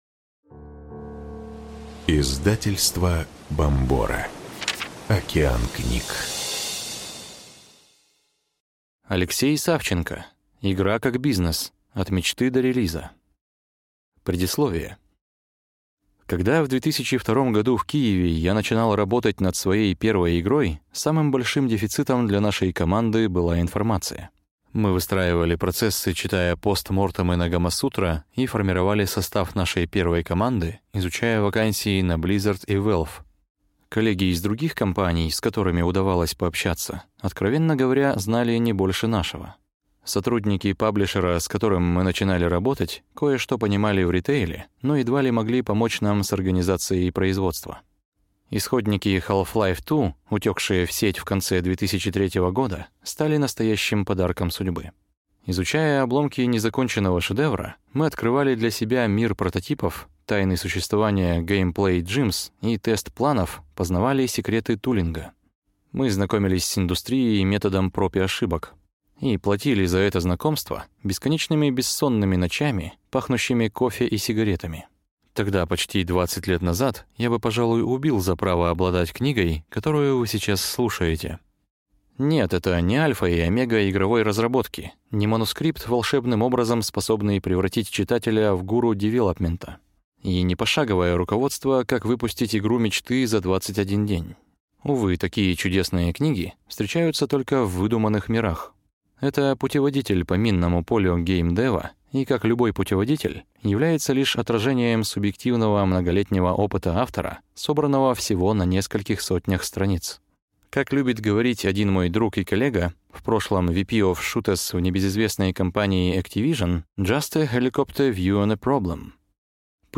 Аудиокнига Игра как бизнес. От мечты до релиза | Библиотека аудиокниг
Прослушать и бесплатно скачать фрагмент аудиокниги